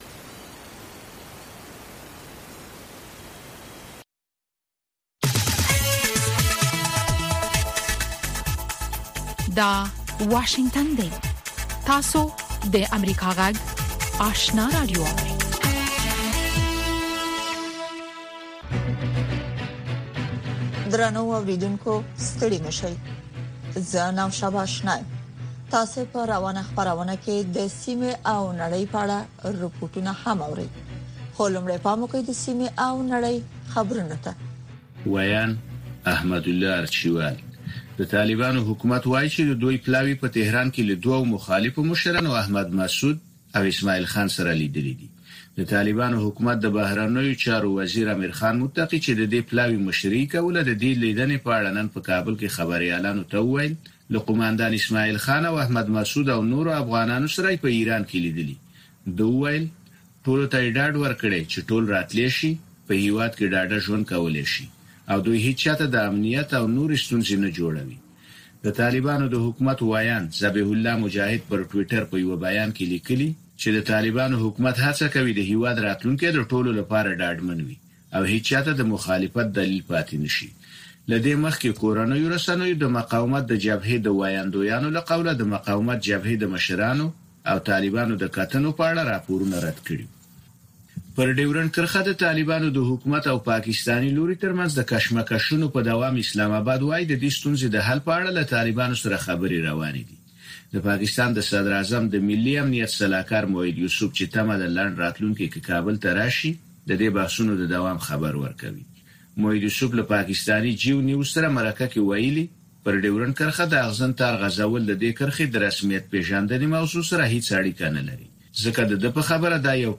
لومړۍ ماښامنۍ خبري خپرونه